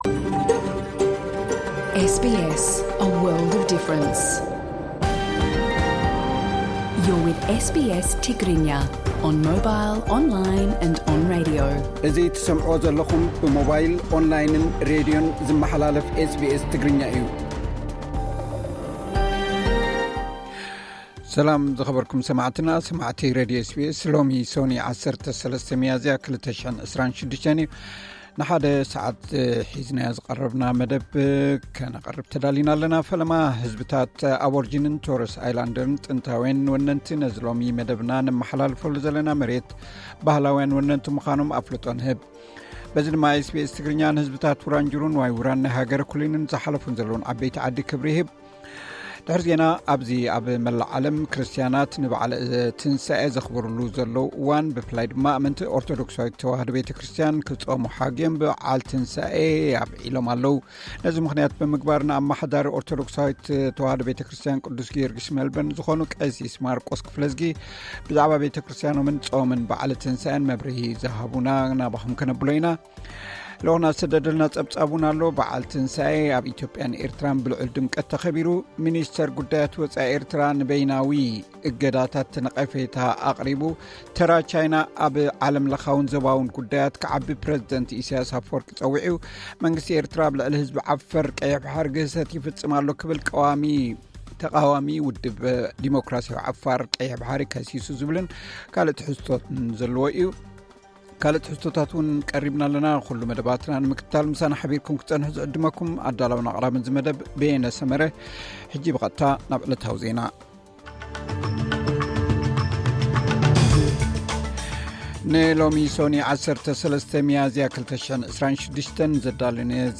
ኣርእስታት ዜና፥